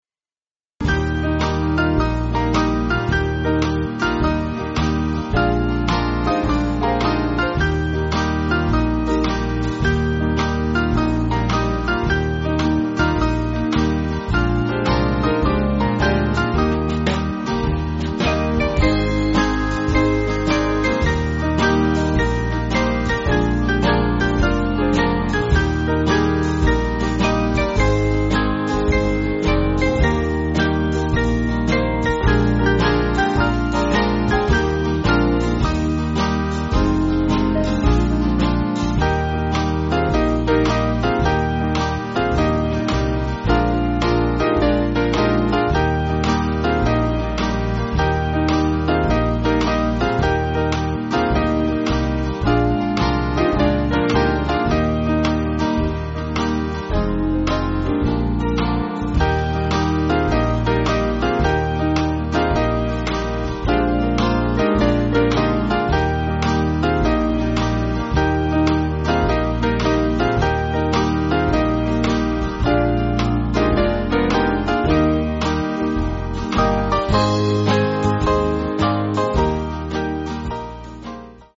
Swing Band
(CM)   5/Eb
Chorus V1,3,5